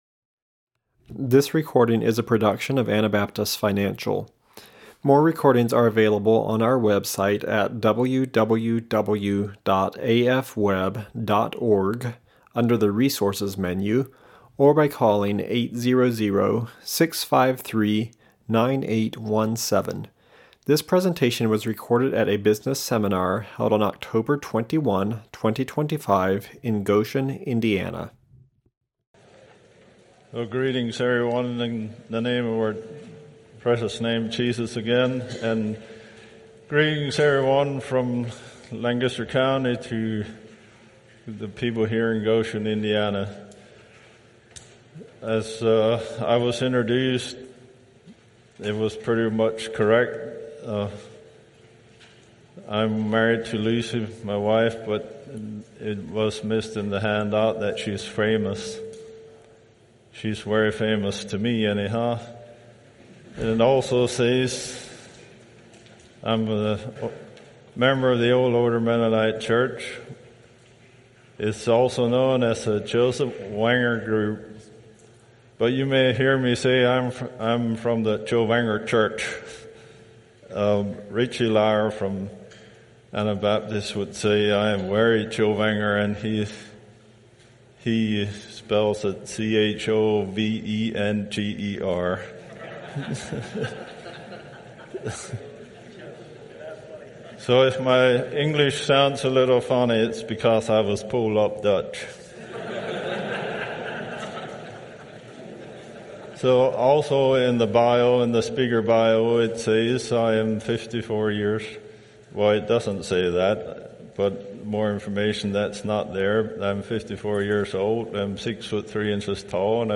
Indiana Business Seminar 2025